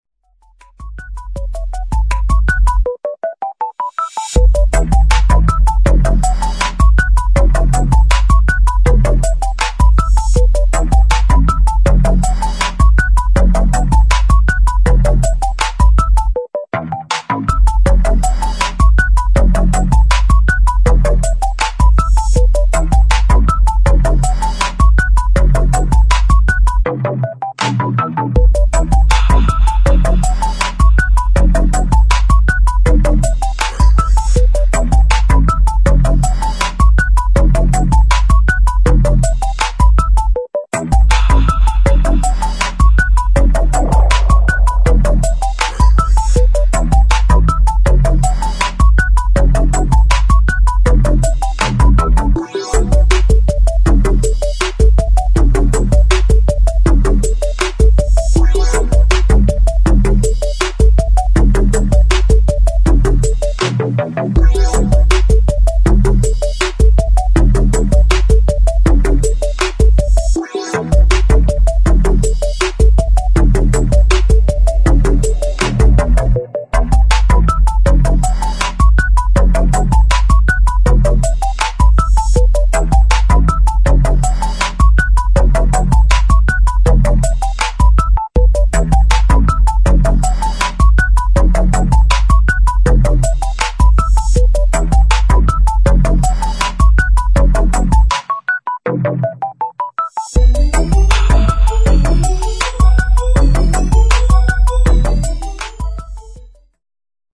[ TECHNO / ELECTRO ]